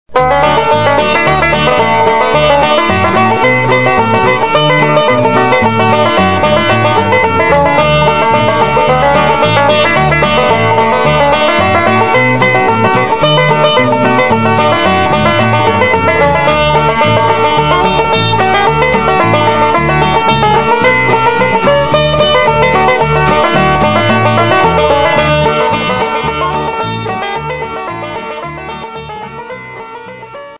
5-String Banjo .mp3 Samples
5-string Banjo Samples - Advanced Level